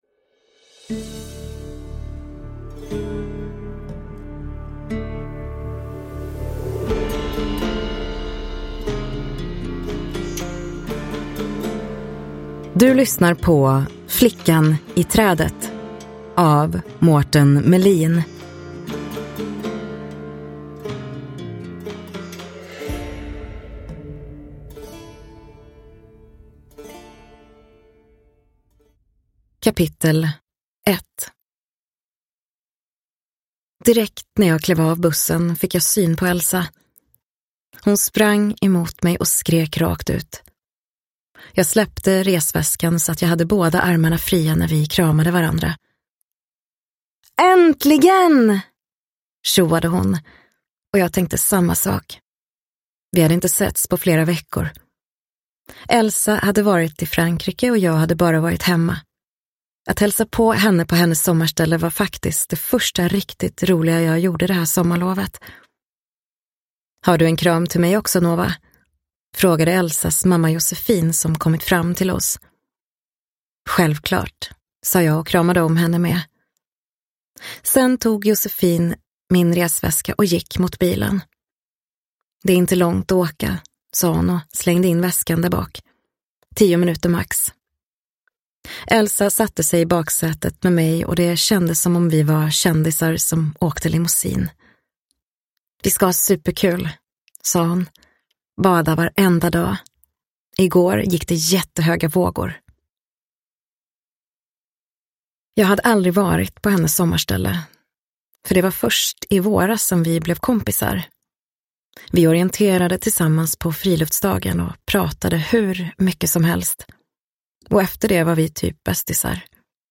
Flickan i trädet – Ljudbok – Laddas ner